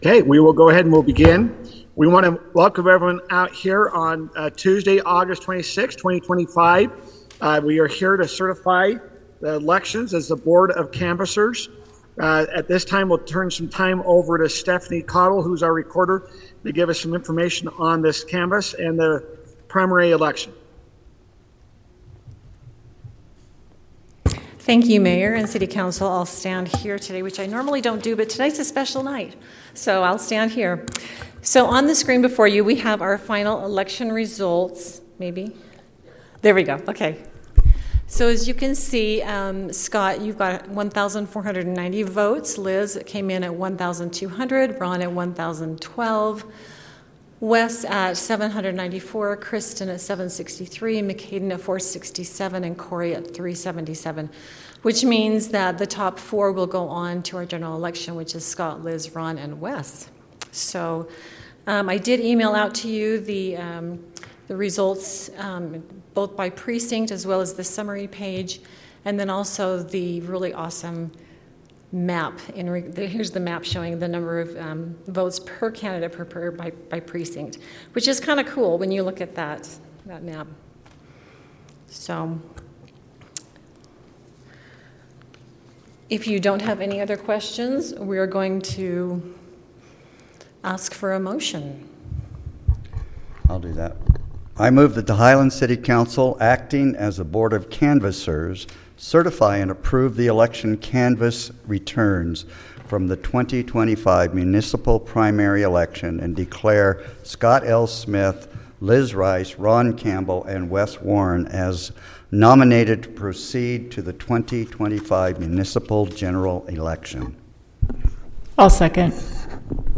Board of Canvassers Meeting
Members of the City Council may participate electronically during the meeting.
5400 West Civic Center Drive, Ste 1